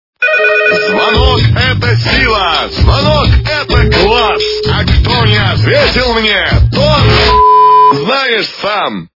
» Звуки » Смешные » Говорящий телефон - Звонок-это сила...
При прослушивании Говорящий телефон - Звонок-это сила... качество понижено и присутствуют гудки.